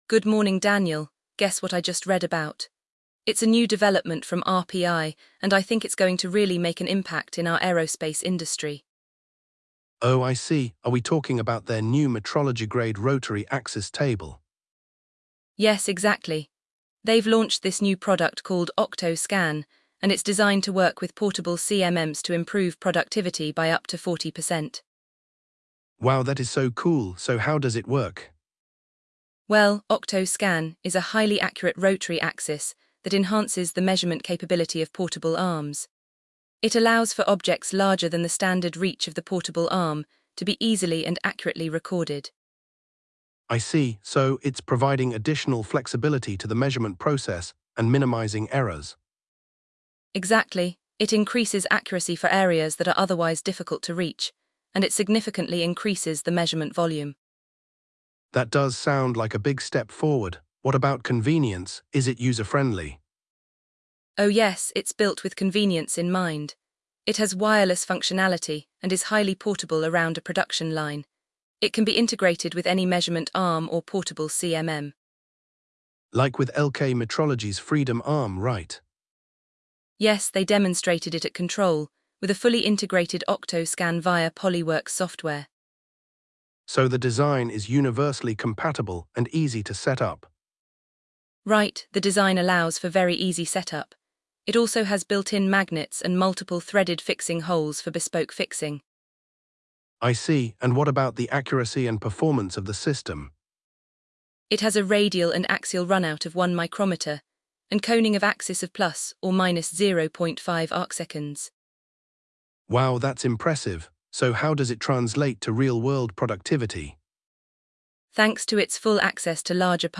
The conversation also covers real-world applications, user-friendliness, and early adoption trends.